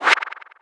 gui_next.wav